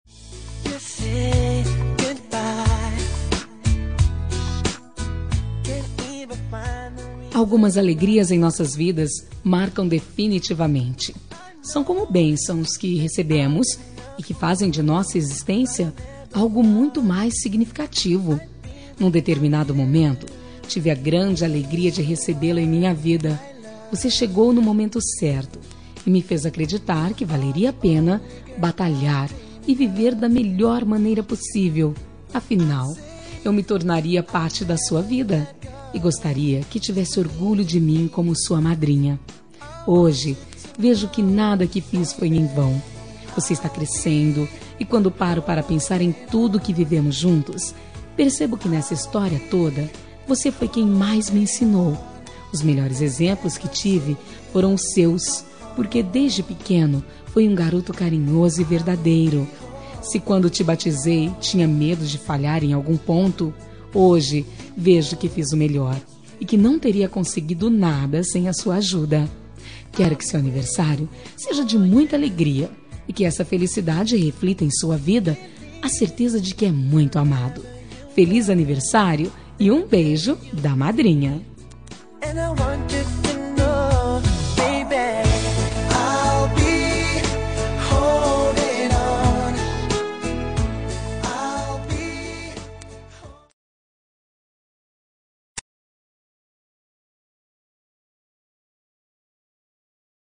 Aniversário de Afilhado – Voz Feminina – Cód: 2356